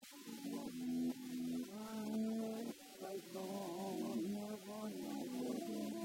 (SI ODE LA MUSICA DELLA CANZONE A ROVESCIO)
VOCE SCONOSCIUTA